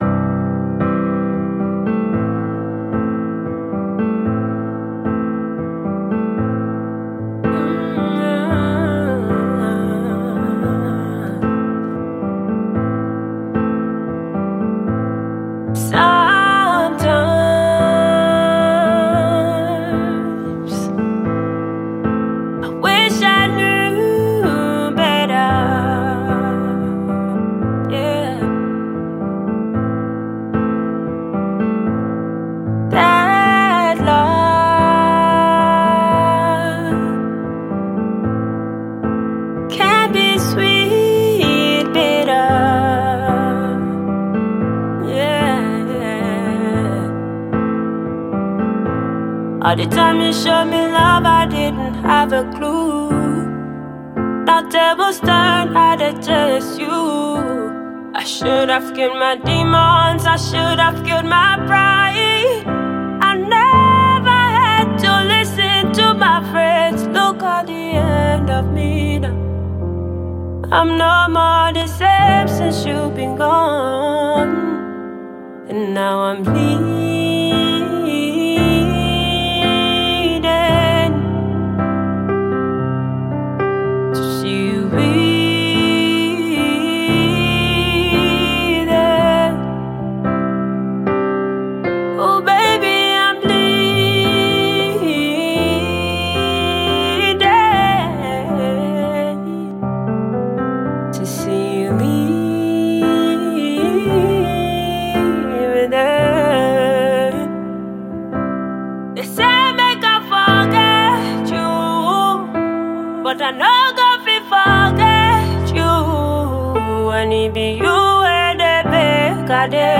Top-notch Ghanaian afro-pop and afrobeat singer